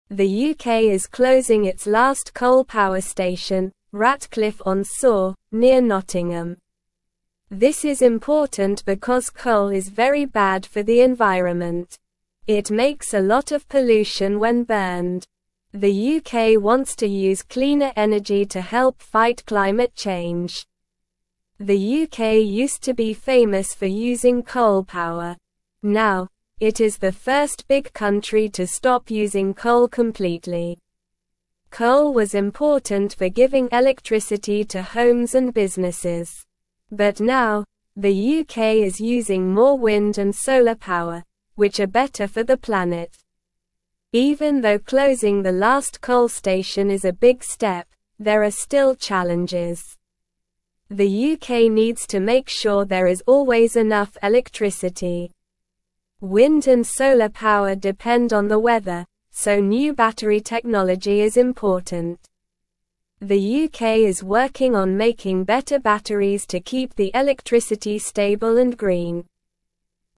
Slow
English-Newsroom-Lower-Intermediate-SLOW-Reading-UK-Stops-Using-Coal-for-Electricity-Fights-Climate-Change.mp3